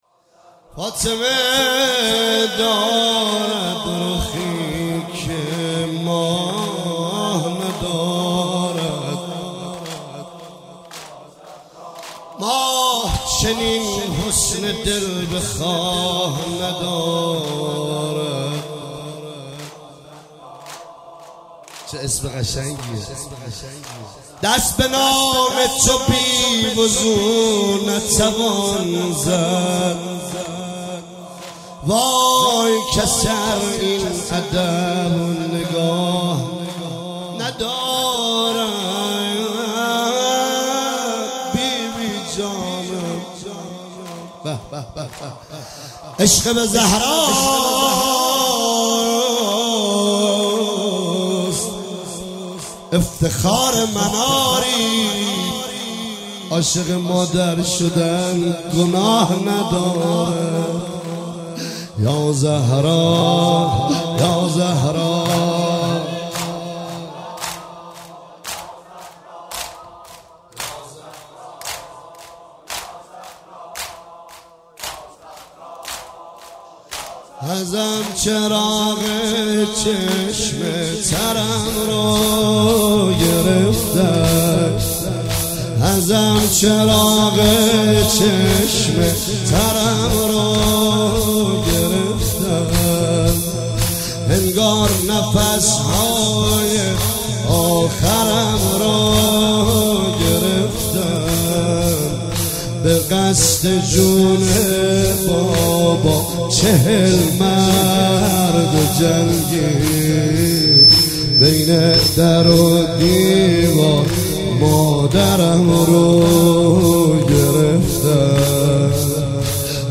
عنوان ایام فاطمیه دوم ۱۳۹۸
مداح
برگزار کننده هیئت خدمتگزاران حضرت عباس
زمینه ازم چراغ چشم ترم رو گرفتن